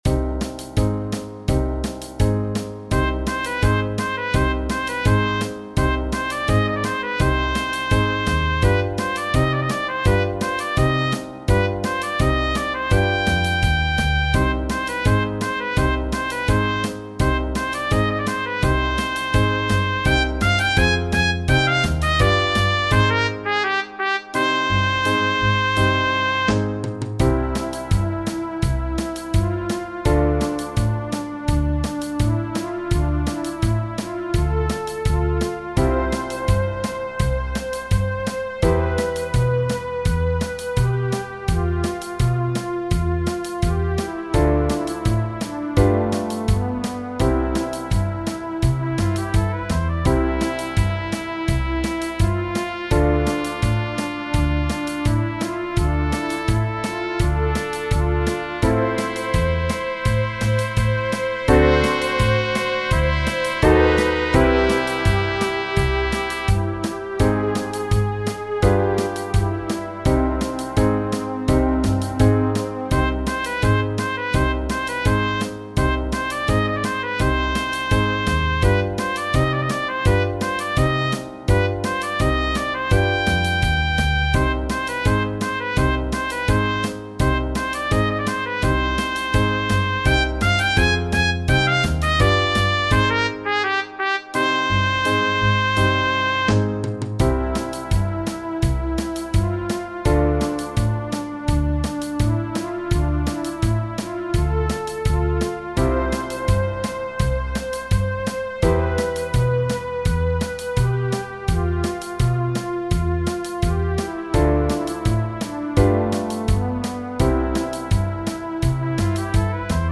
indicato come oriental foxtrot